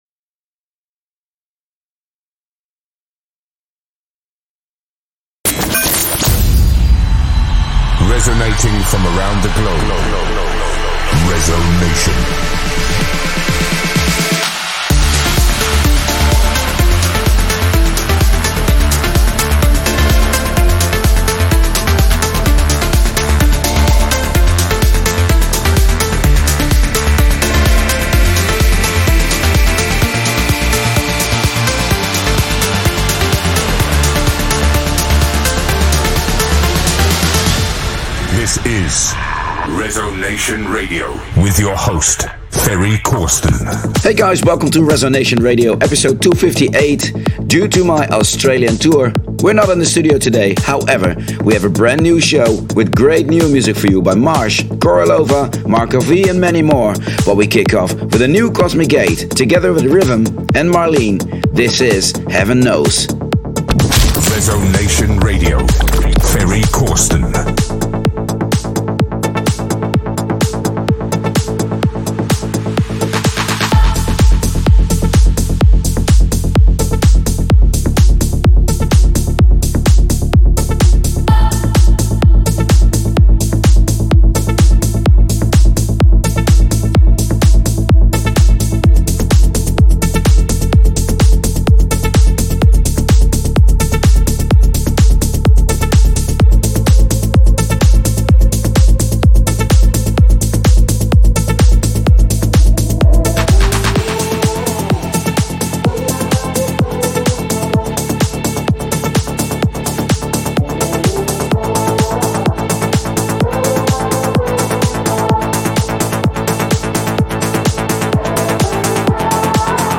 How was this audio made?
While touring Australia